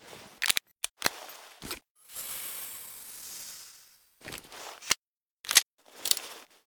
syringe1.ogg